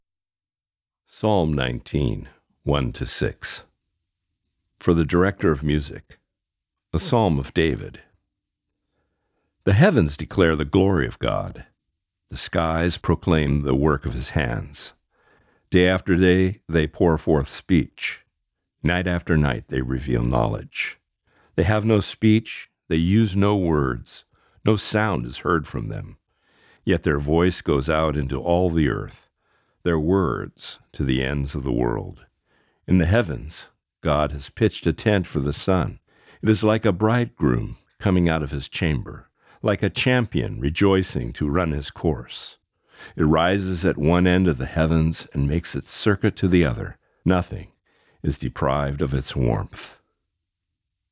Reading: Psalm 37:1-6